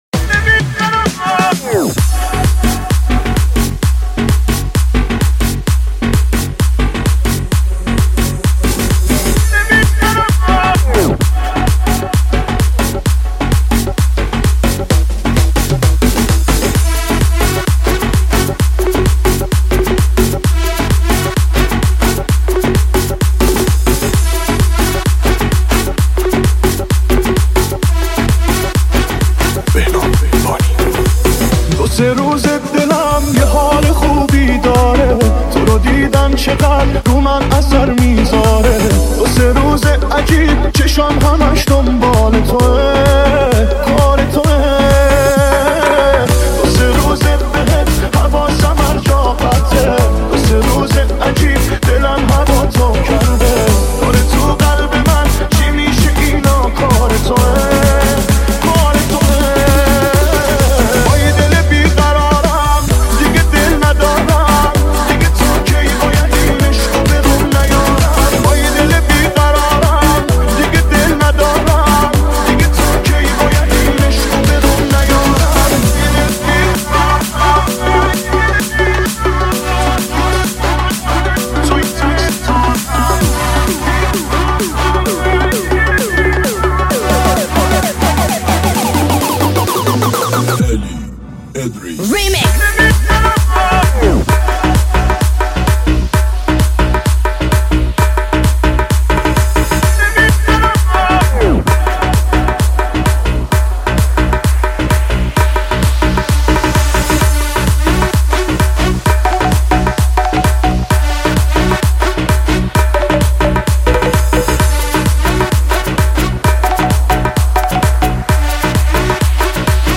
ریمیکس شاد ارکستی
ریمیکس شاد تریبال برای رقص